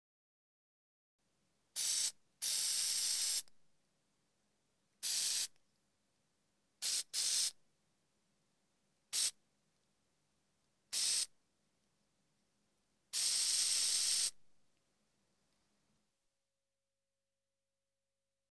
Звук аэрозоля дезодорант или освежитель воздуха